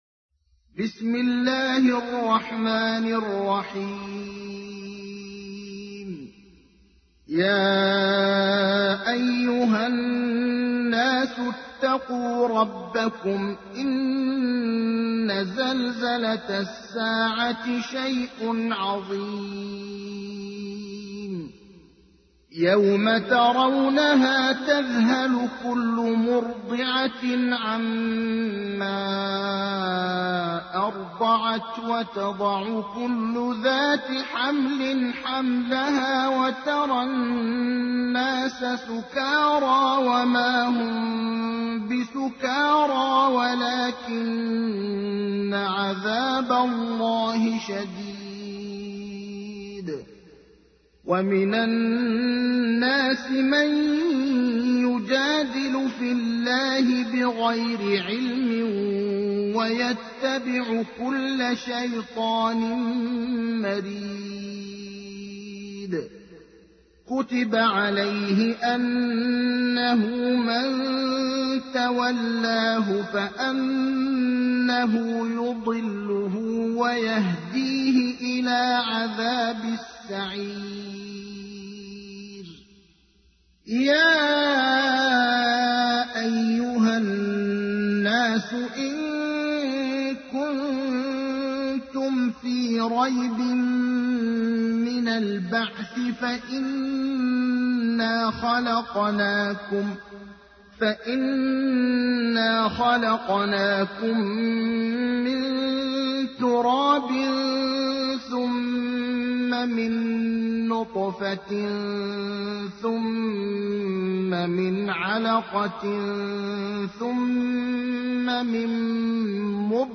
تحميل : 22. سورة الحج / القارئ ابراهيم الأخضر / القرآن الكريم / موقع يا حسين